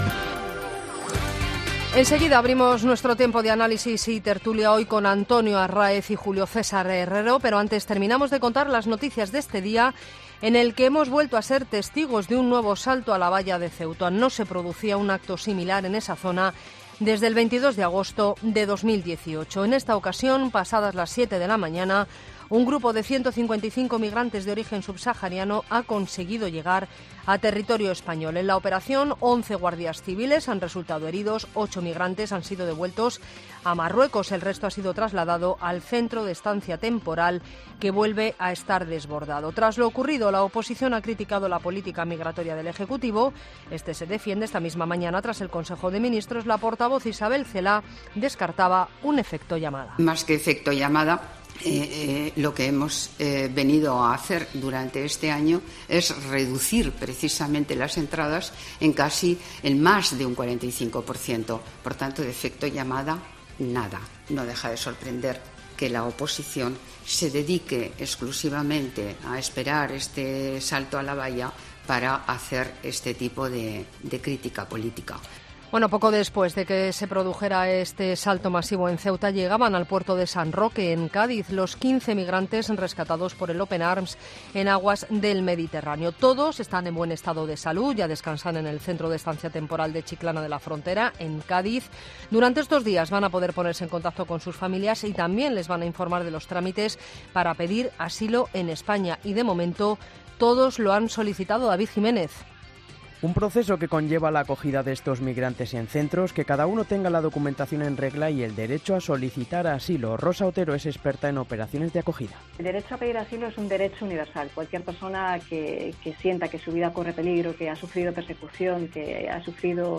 Boletín de noticias COPE del 30 de agosto a las 22.00 horas